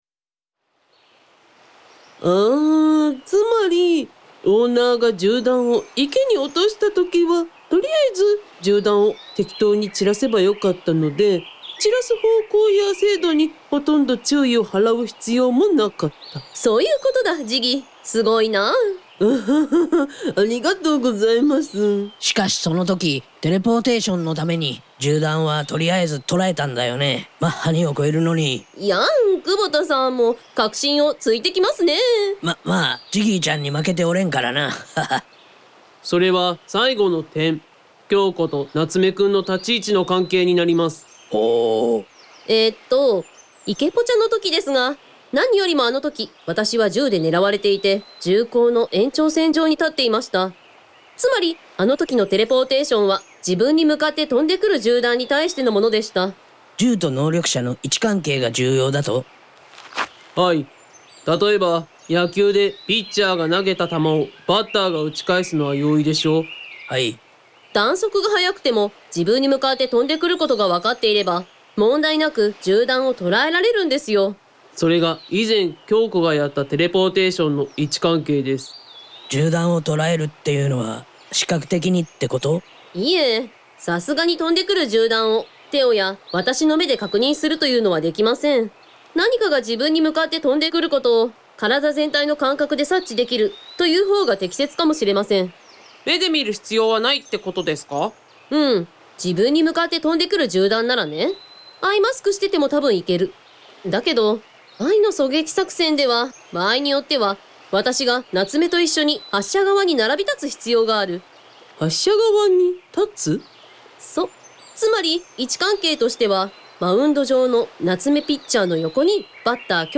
Team Happy Hill制作のオーディオドラマドラマ『私はベロニカ』シリーズ他ネットラジオ等、音声配信ブログです